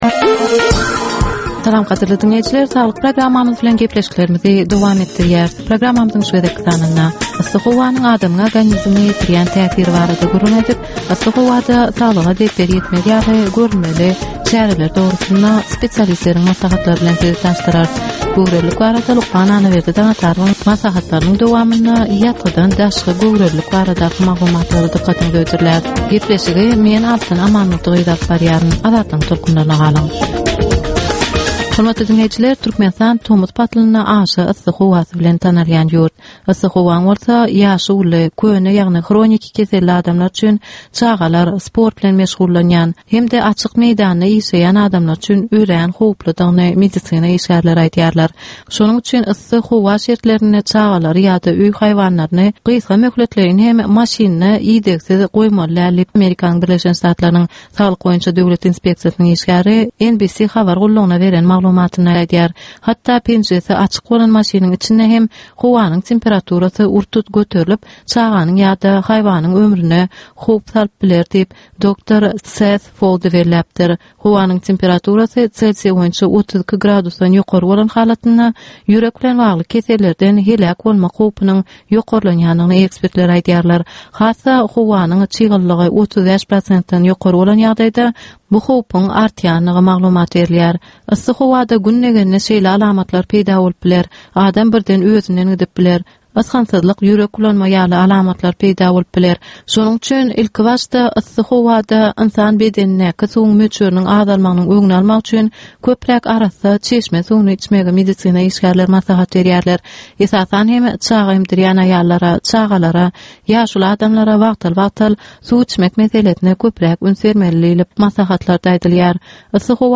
Ynsan saglygyny gorap saklamak bilen baglanysykly maglumatlar, täzelikler, wakalar, meseleler, problemalar we çözgütler barada 10 minutlyk ýörite geplesik.